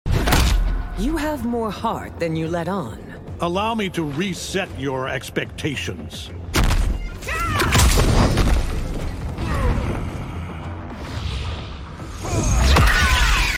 Omni Man Vs SindelRoyal Screams Sound Effects Free Download